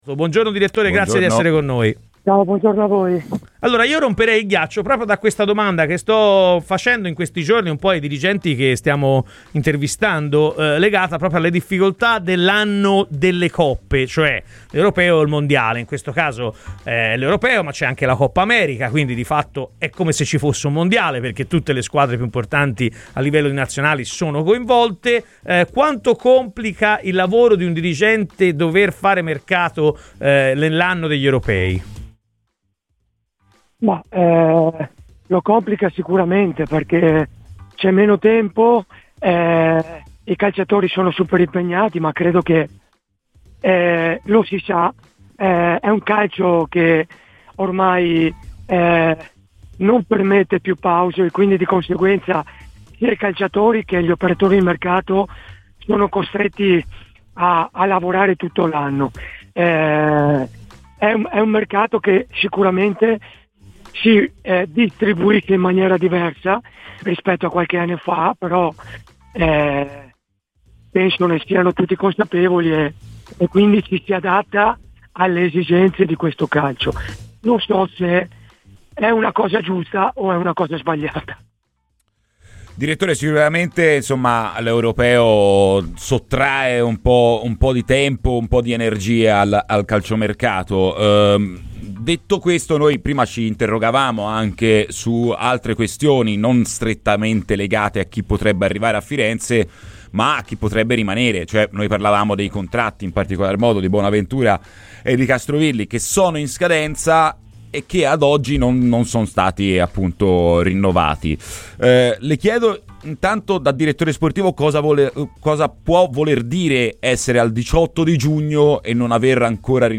è intervenuto ai microfoni di Radio FirenzeViola durante la trasmissione 'Chi si compra?'